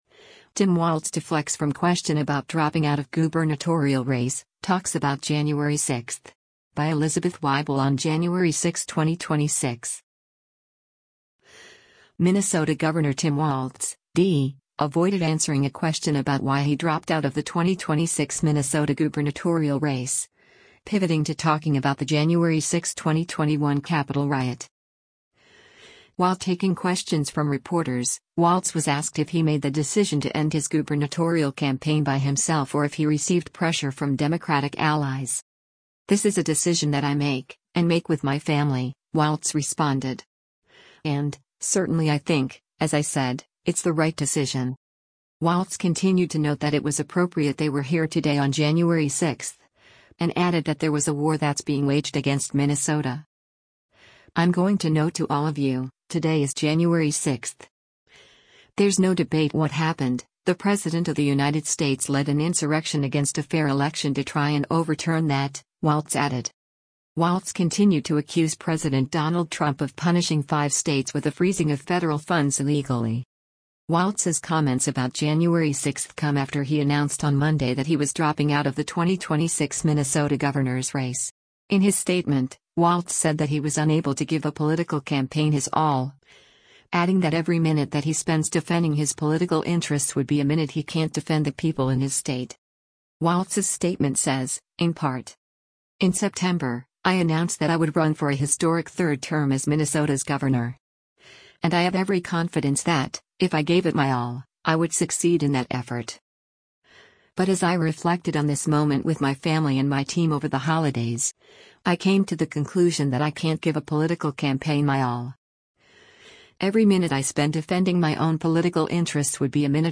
Minnesota Gov. Tim Walz speaks during a press conference at the State Capitol building on
While taking questions from reporters, Walz was asked if he made the decision to end his gubernatorial campaign by himself or if he received “pressure from Democratic allies.”